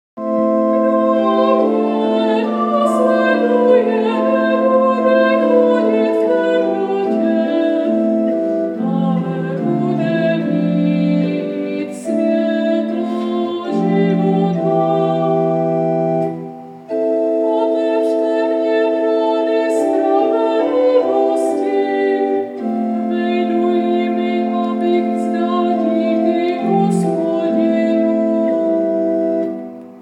Nápěv 2 antifony a žalmu
2.-antifona-a-zalm_cut_30sec.mp3